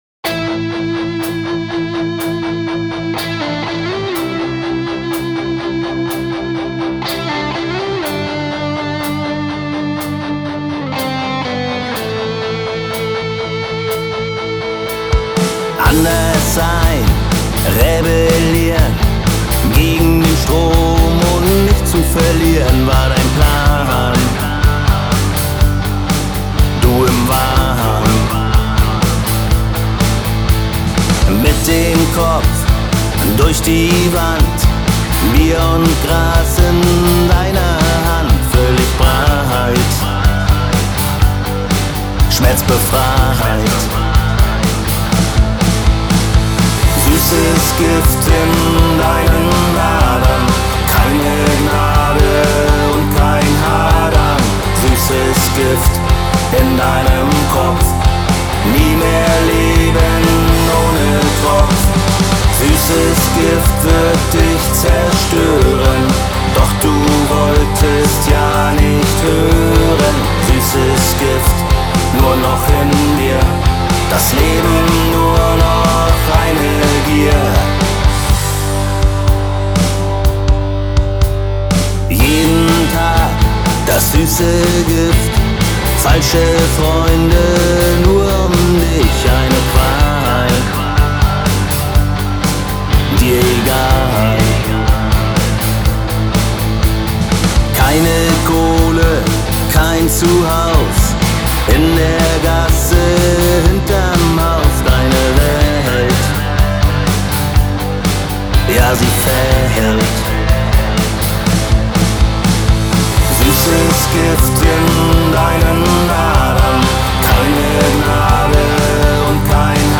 Ehrliche deutsche Rockmusik mit Haltung und Gefühl.